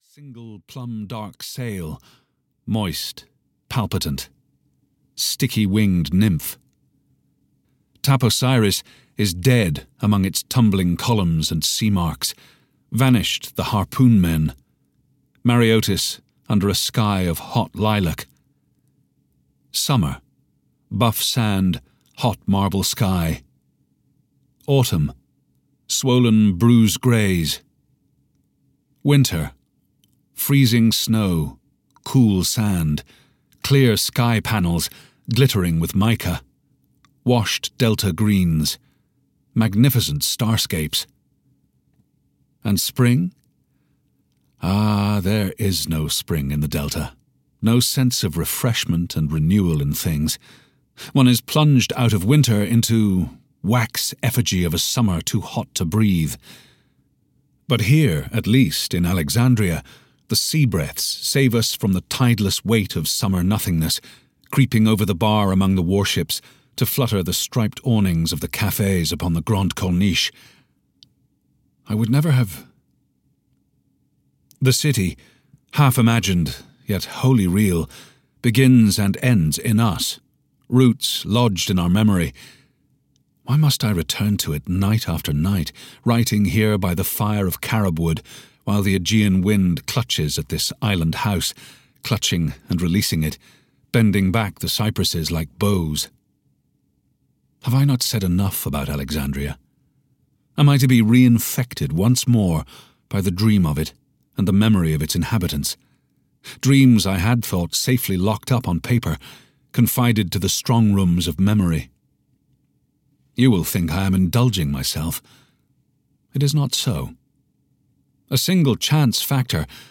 Audio knihaBalthazar (EN)
Ukázka z knihy